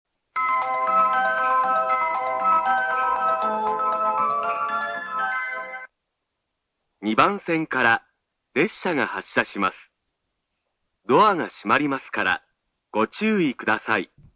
２番線発車メロディー 曲は「小川のせせらぎ」です。